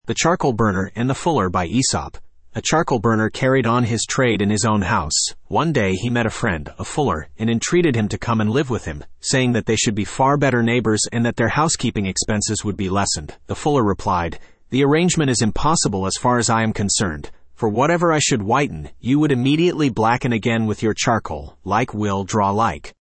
Studio (Male)